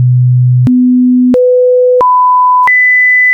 octaafmiddenfrequenties
In onderstaand voorbeeld hoor je een opeenvolging van de volgende octaafmiddenfrequenties:
125, 250 Hz, 500 Hz, 1000 Hz en 2000 Hz:
octaaf.wav